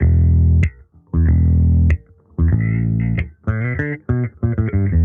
Index of /musicradar/sampled-funk-soul-samples/95bpm/Bass
SSF_JBassProc1_95E.wav